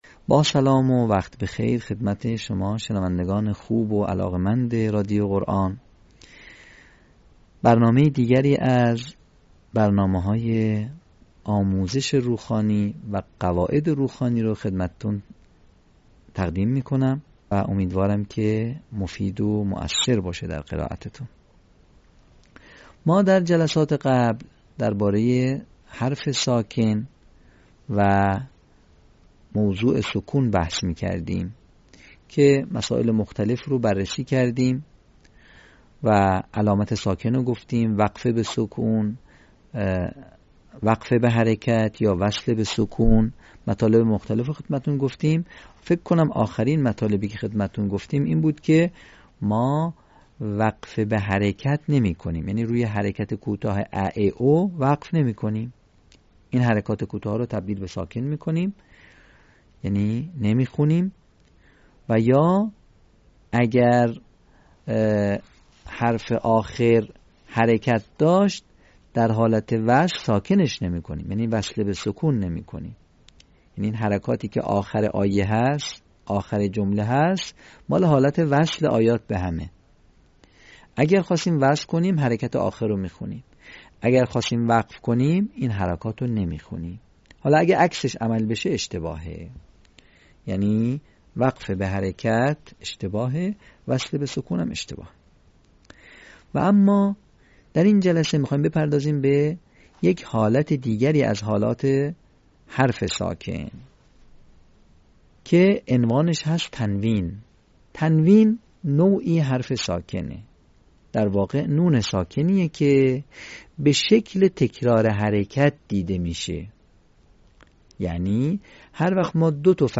صوت | آموزش روخوانی «تنوین حرف ساکن»